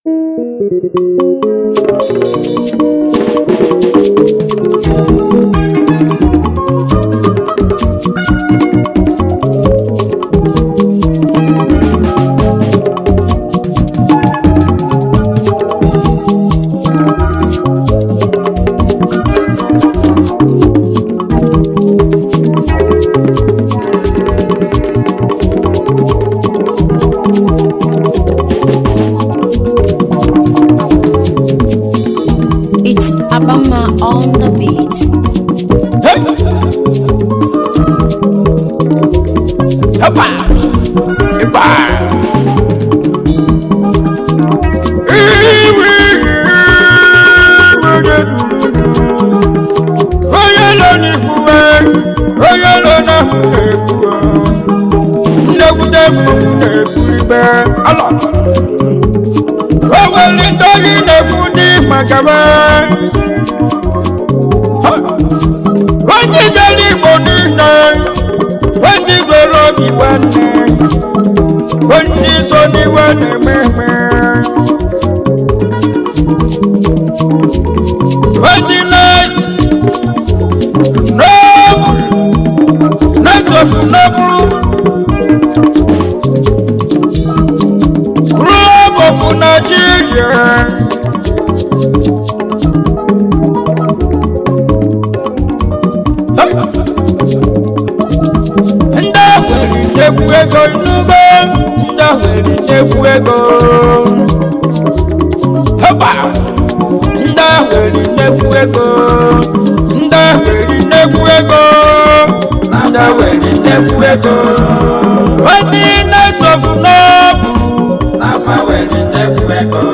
highlife track
highlife tune